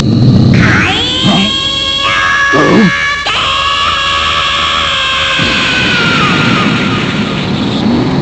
In questa pagina potete trovare i suoni in formato WAV / MP3 dei vari attacchi e delle tecniche speciali dei personaggi.